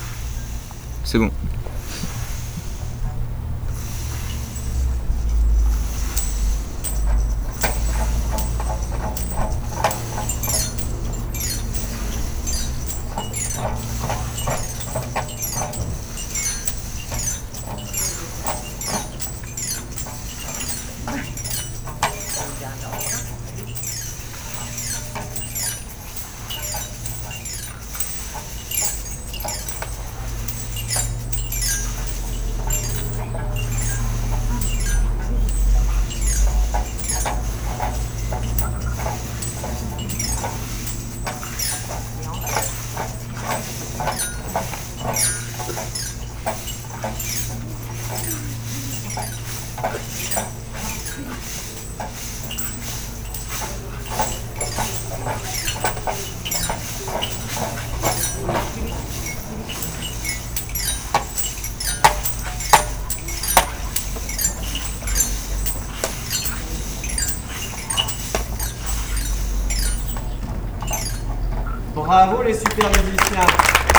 -les bâtons de pluie
-les crécelles
-les élastophones
-les xylophone
-les maracas
Ensuite nous avons expérimenté, manipulé, produits des sons en essayant chaque instrument.
Pour finir, chaque groupe a joué un petit concert avec les instruments.